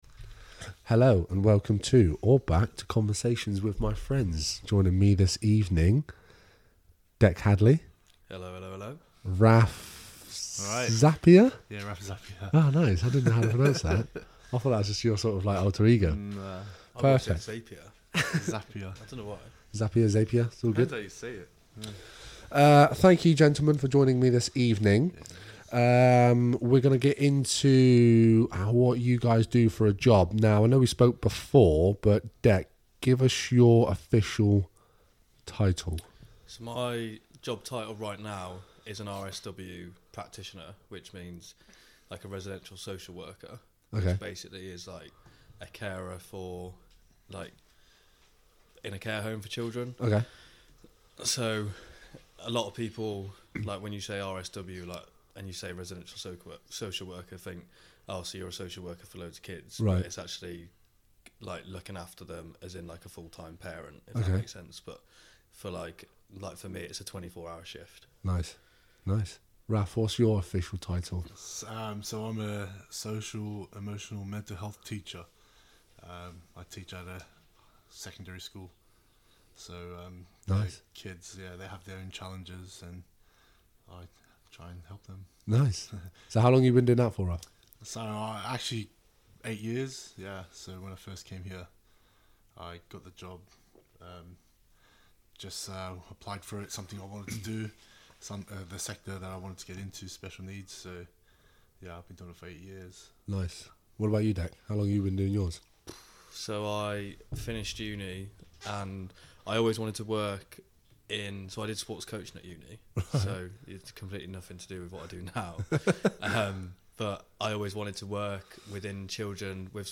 Apologies for the quietness of the guests. It sounded loud in my headphones (!)